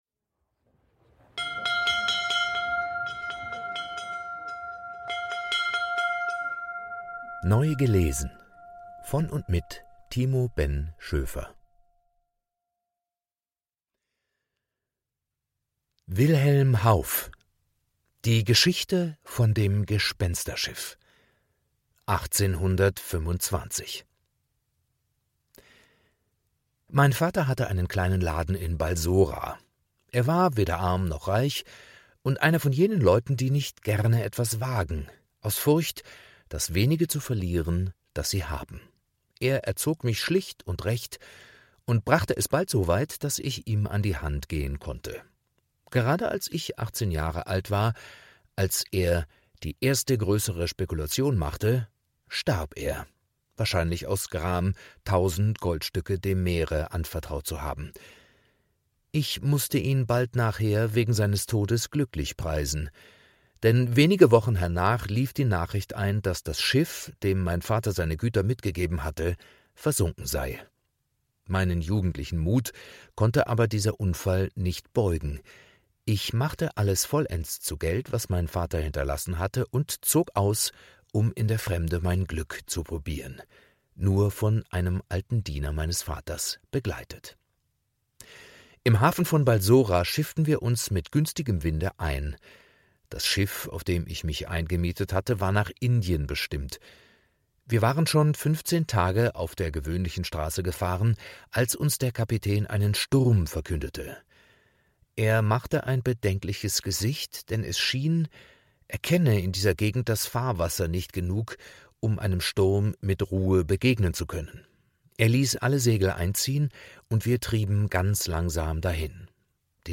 Literatur des 19.Jahrhunderts,vorgelesen